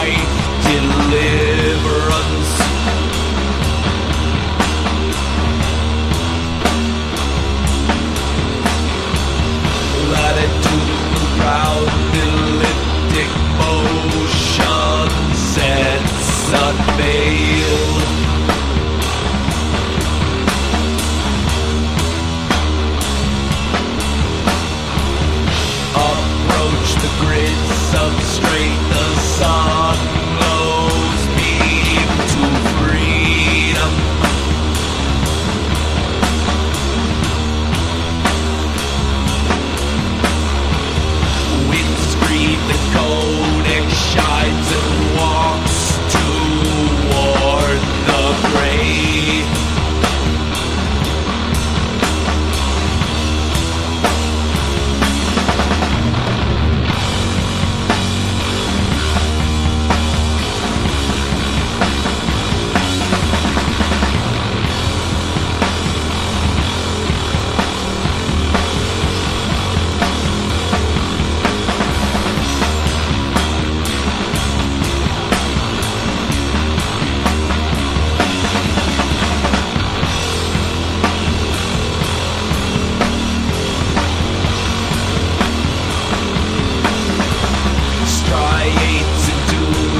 1. 00S ROCK >
螺旋グルーヴと黒魔術ボーカルによる深淵なサウンドはだんだんと貴方を別世界へ引きずり込みます。。
MIXTURE / LOUD / HR